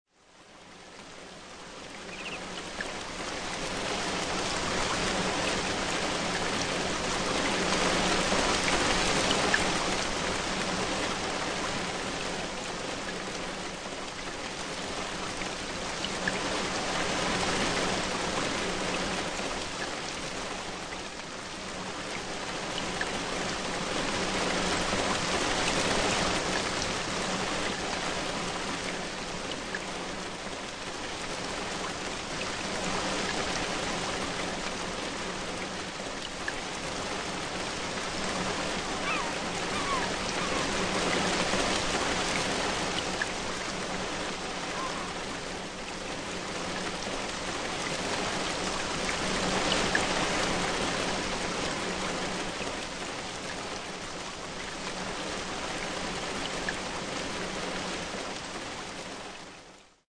Torrential Rain